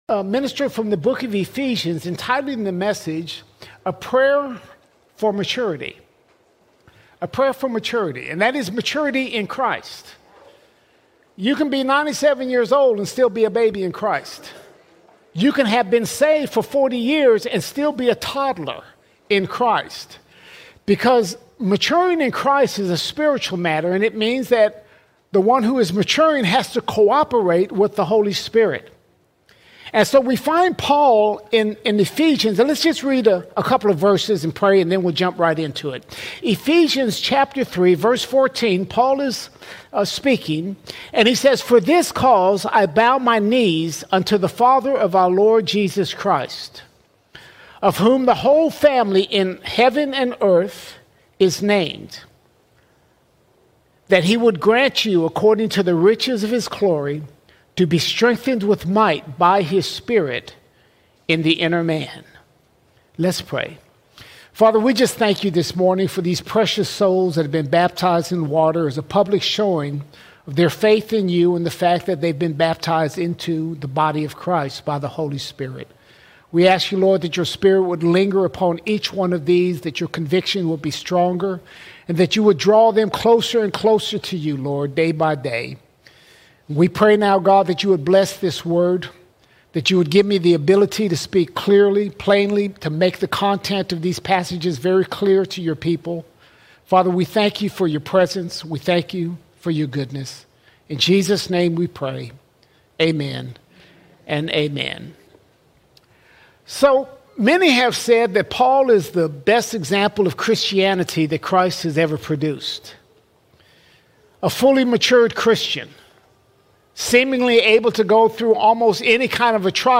9 September 2025 Series: Sunday Sermons All Sermons A PRAYER FOR MATURITY A PRAYER FOR MATURITY Maturing in Christ is a spiritual matter.